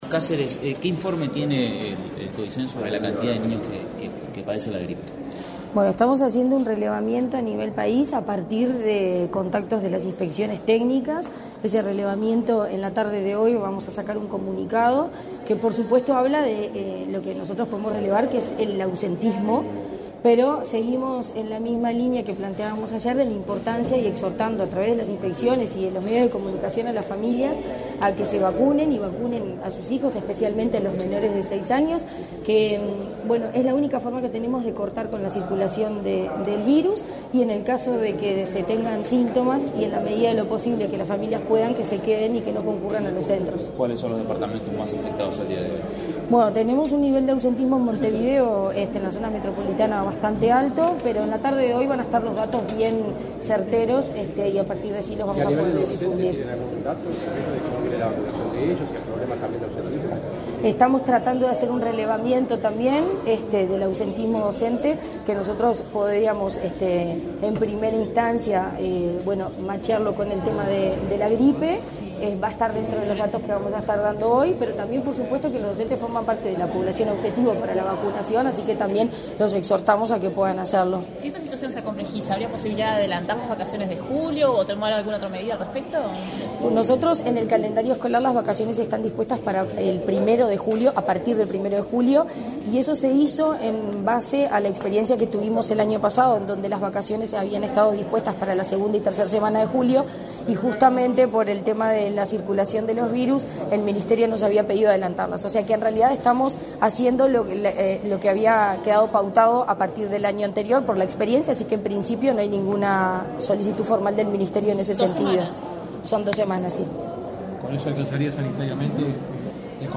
Declaraciones a la prensa de la presidenta de ANEP, Virginia Cáceres
Declaraciones a la prensa de la presidenta de ANEP, Virginia Cáceres 29/05/2024 Compartir Facebook X Copiar enlace WhatsApp LinkedIn Este 29 de mayo se realizó el lanzamiento de la campaña Ni Silencio Ni Tabú 2024. Antes del evento, la presidenta de la Administración Nacional de Educación Pública (ANEP), Virginia Cáceres, realizó declaraciones a la prensa.